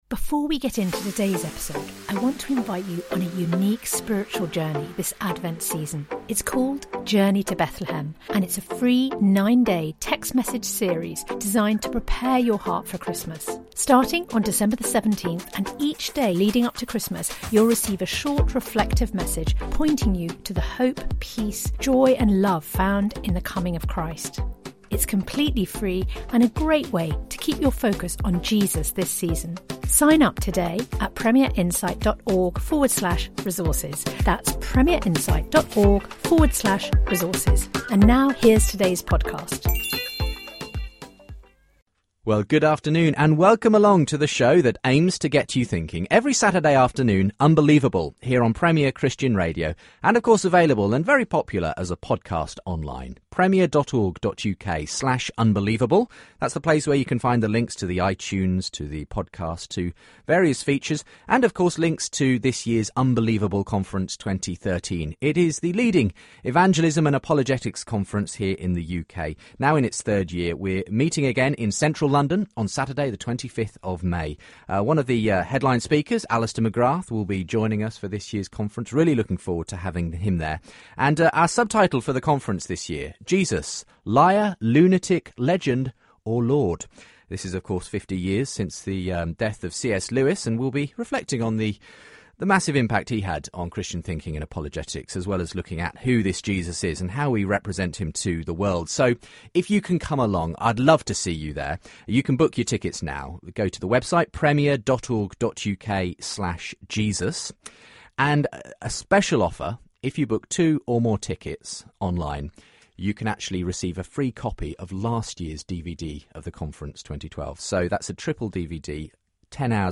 from three atheist callers